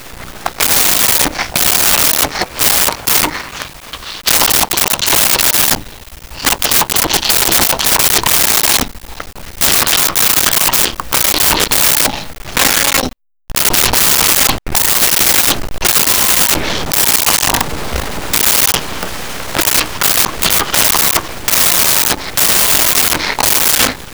Laughing Baby
Laughing Baby.wav